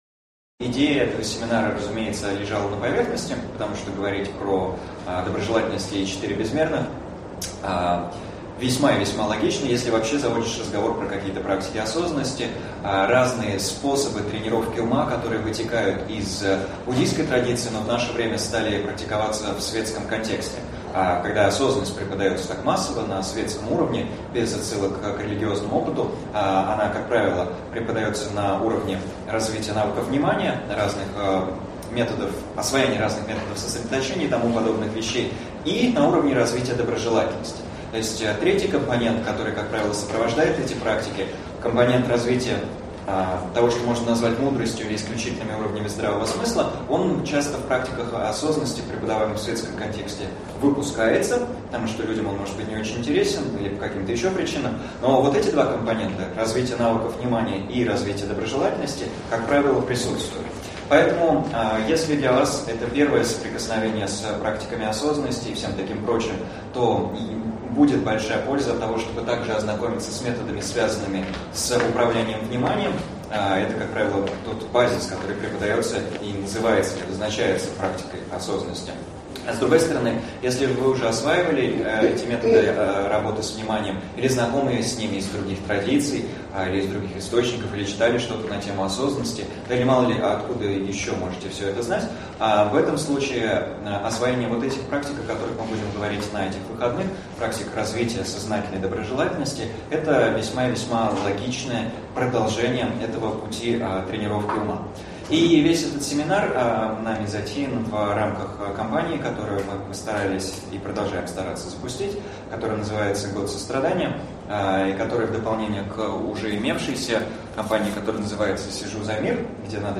Аудиокнига Осознанная доброжелательность и четыре безмерных. Часть 1 | Библиотека аудиокниг